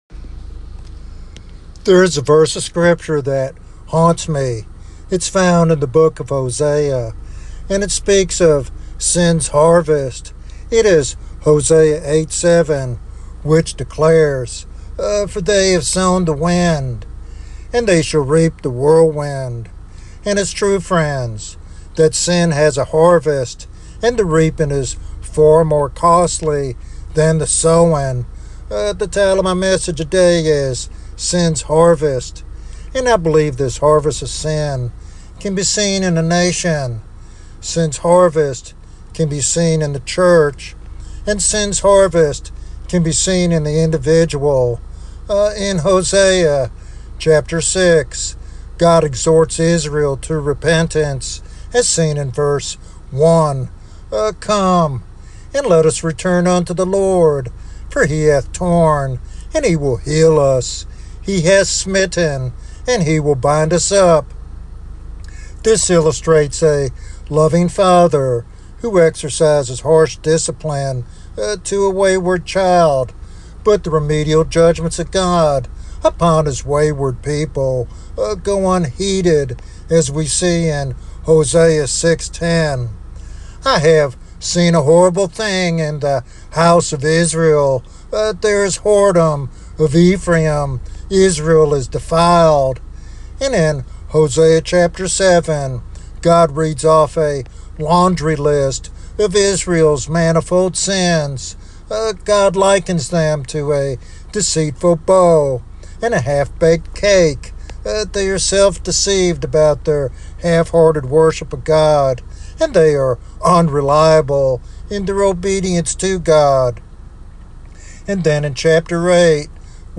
This sermon challenges listeners to recognize the seriousness of sin and the hope found in God's discipline and restoration.